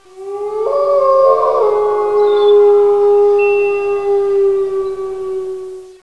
wolves1.wav